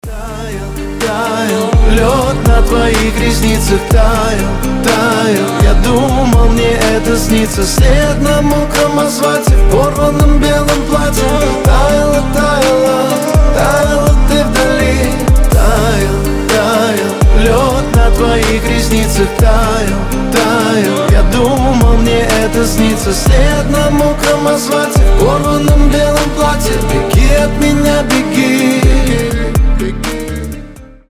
• Качество: 320, Stereo
лирика
грустные
мелодичные